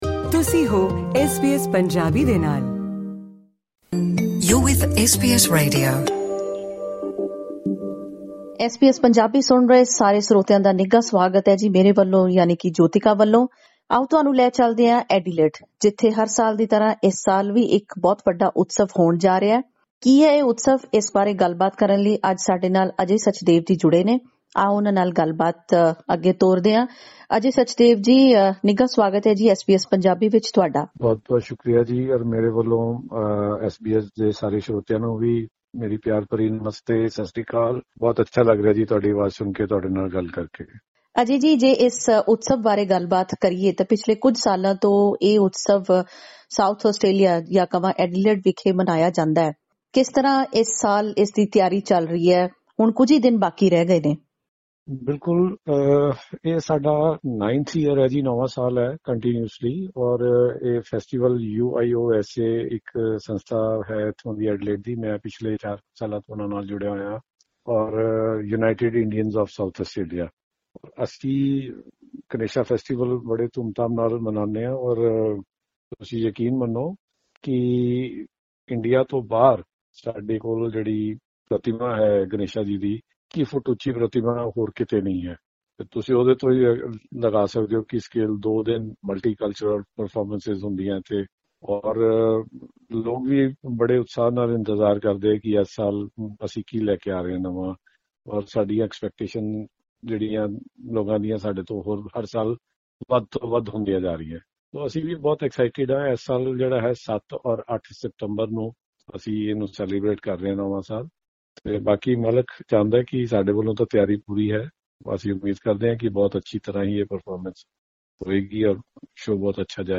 ਖਾਸ ਗੱਲਬਾਤ